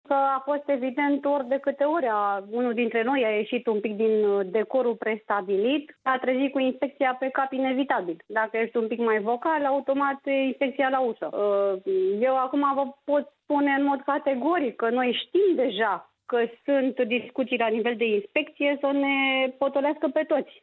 Judecătoarea Sorina Marinaș, de la Secția Penală a Curții de Apel Craiova, a declarat aseară, la Euronews România, că este sigură că urmează „repercusiuni” ale Inspecției Judiciare împotriva magistraților „care au îndrăznit” să vorbească despre problemele din justiție.
Cred totuși că, după reacția publică a magistraturii, se va întârzia puțin”, a declarat Sorina Marinaș, într-o intervenție telefonică la Euronews.